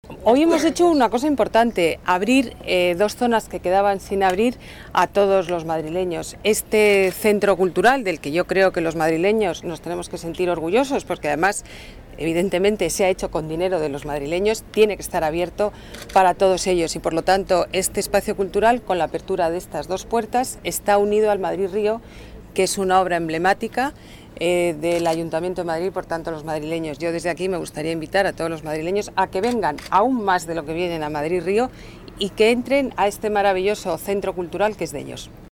Nueva ventana:Declaraciones de la alcaldesa Ana Botella: inauguración de dos pasos que unen Matadero con Madrid Río